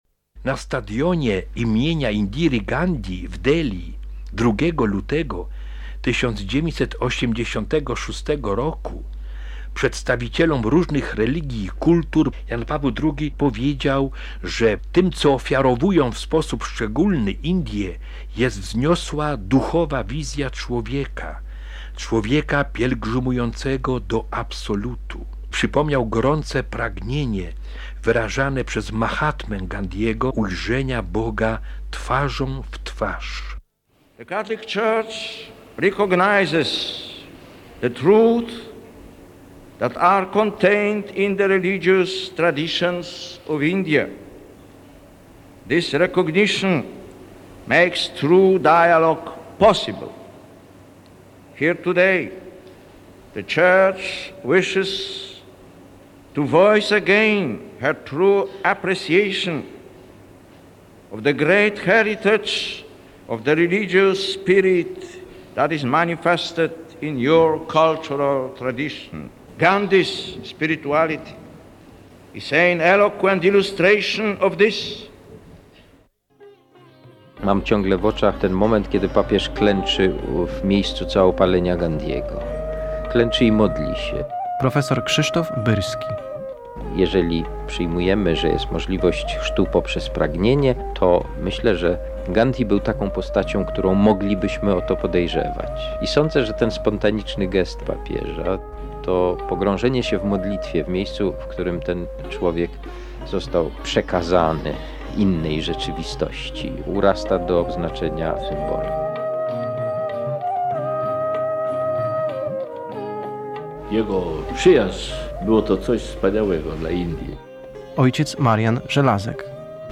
Indie 1986 r. - fragment audycji PR